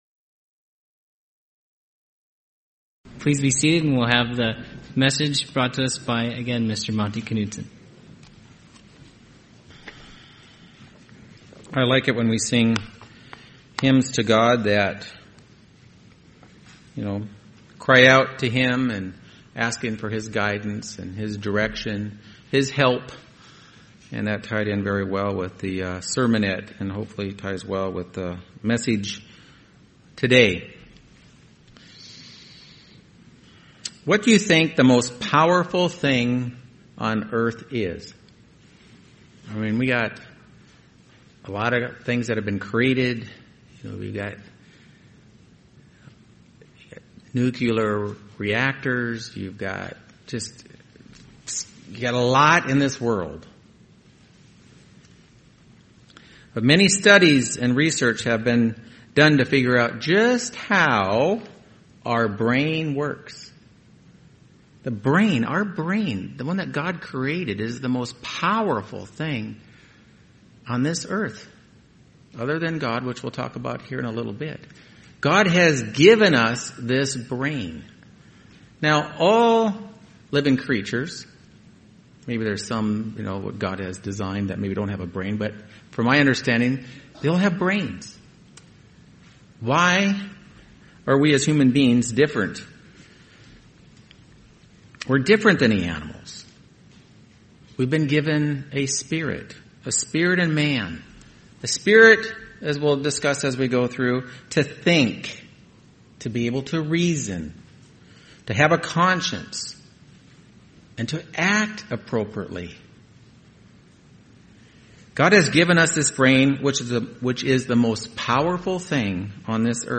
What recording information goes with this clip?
Given in Phoenix East, AZ